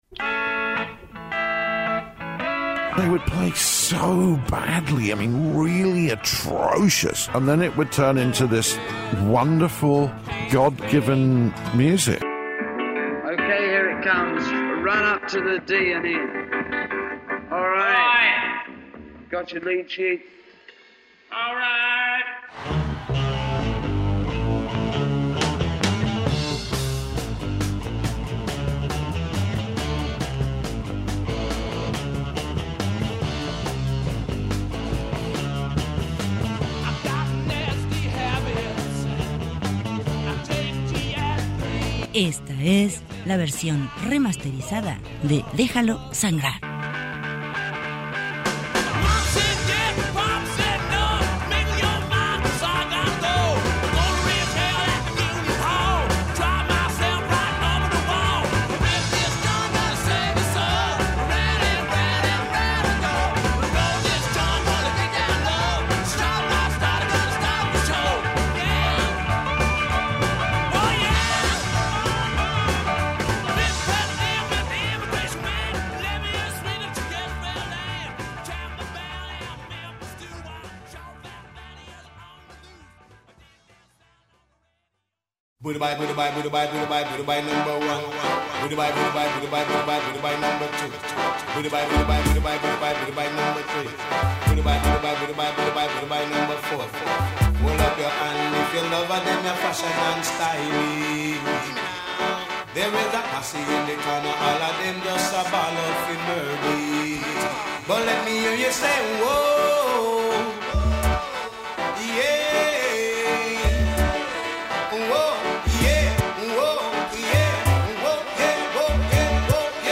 black music